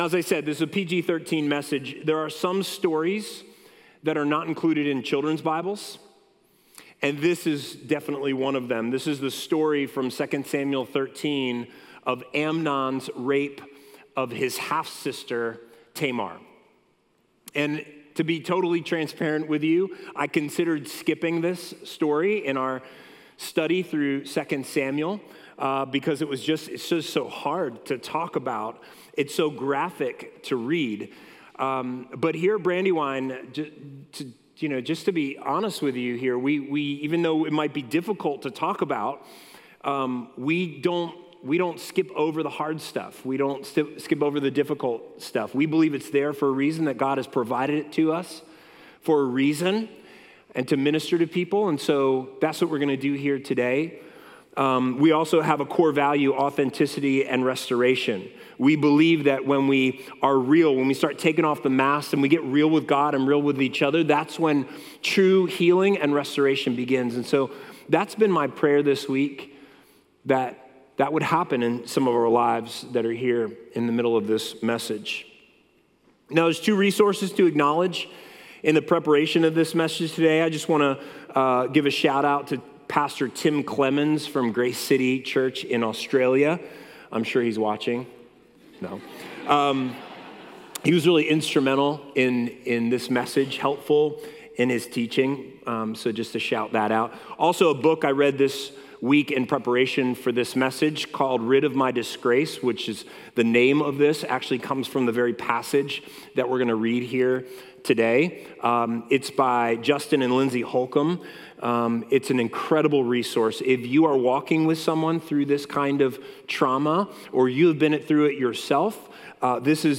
A message from the series "The Life of David." David and Jonathan model what true friendship looks like. We'll look at the marks of a true friend and how to be this kind of friend in the lives of others.